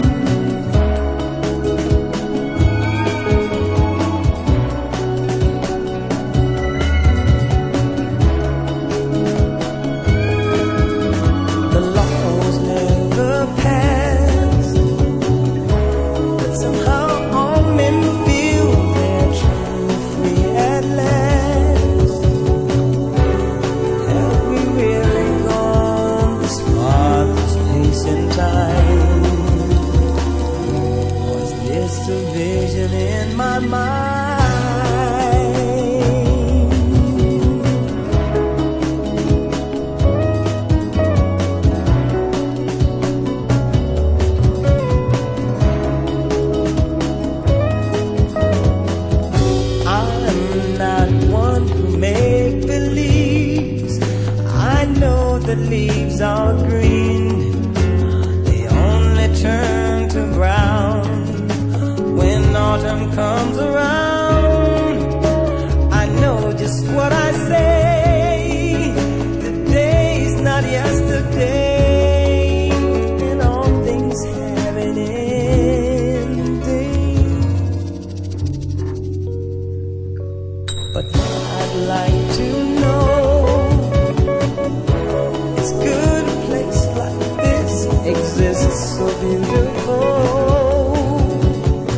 spirited nu-jazz version
sweeping synths and skittering beats